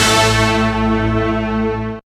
hitTTE68017stabhit-A.wav